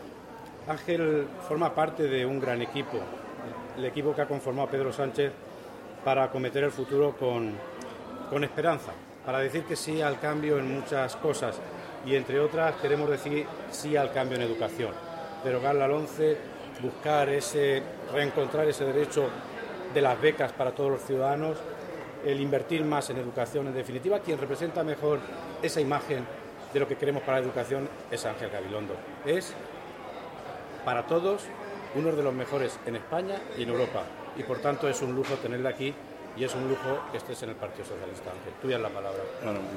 El ex ministro socialista de Educación, Ángel Gabilondo, ha expuesto en la Filmoteca de Albacete las propuestas socialistas en materia de Educación.
Cortes de audio de la rueda de prensa